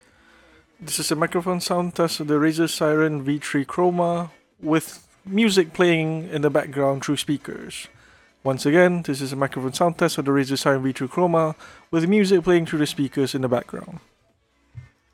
We got a few recordings down with the Razer Seiren V3 Chroma to show how well it performs.
Moving on to our 3rd recording, this one has music playing in the background through speakers and with Noise Gate enabled via Razer Synapse. You can hear a bit of the song at the start, and it’s a bit more noticeable when I speak but other than that, it does its job fairly decently.